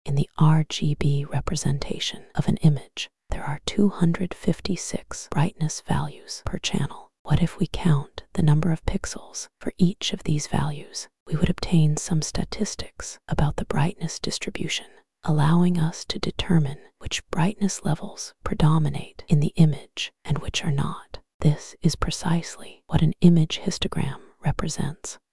А для ASMR — kokoro v1.0 af_nicole:
Он даже лучше, чем в Azure!